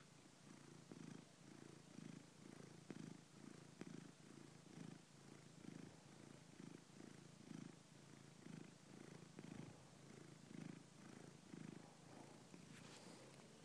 Roxy purring